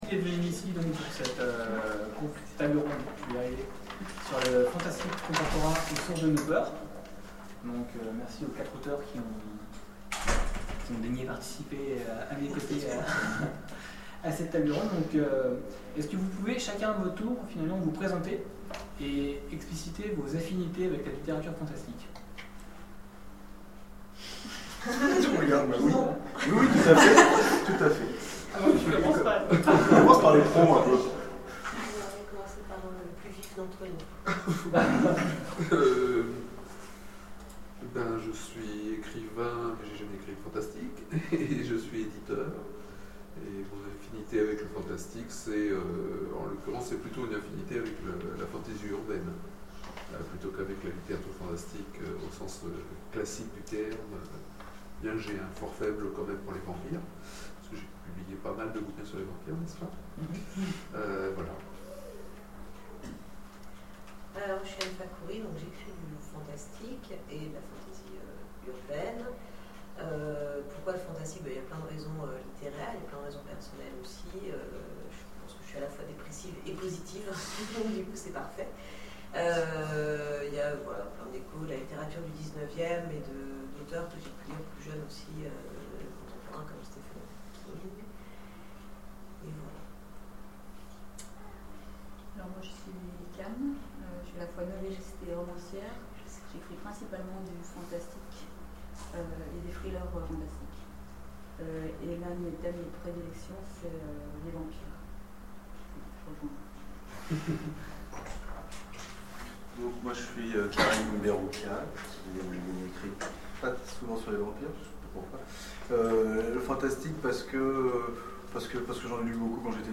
Oniriques 2013 : Conférence Le fantastique contemporain, aux sources de nos peurs
Conférence